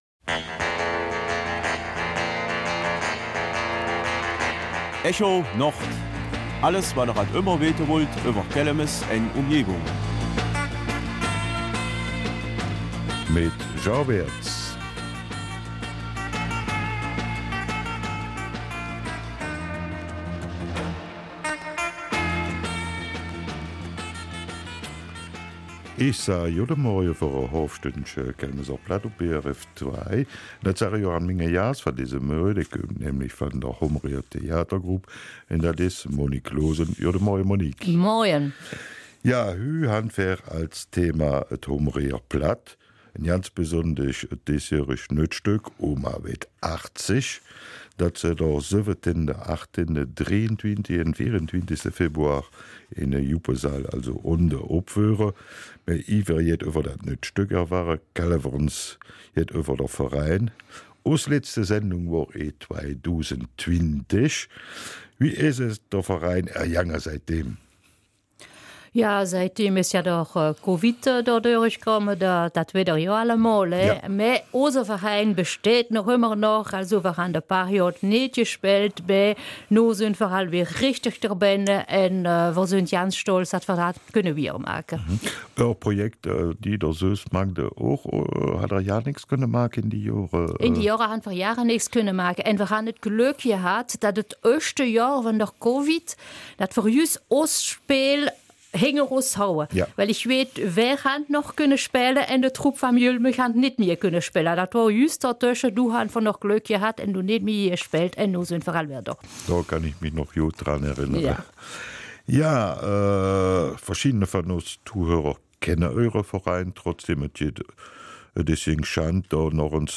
Kelmiser Mundart: Theater auf Platt in Hombourg